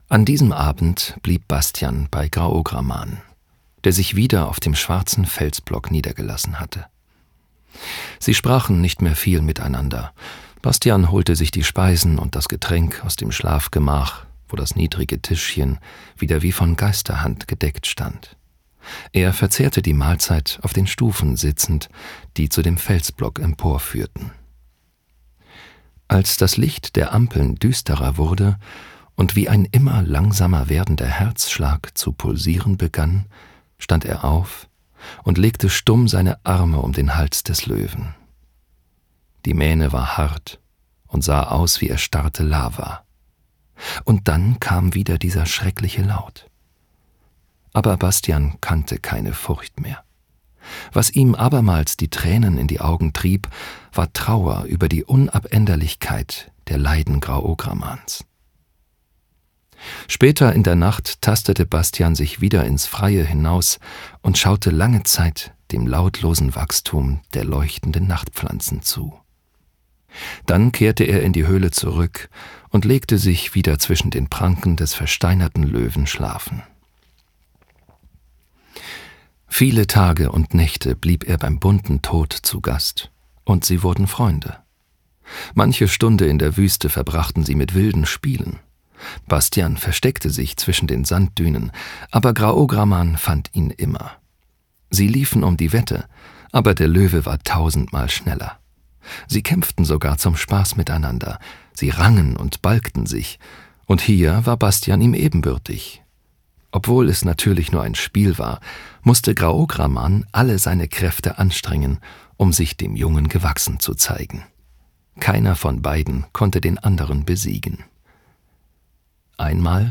•       HÖRBUCH & HÖRSPIEL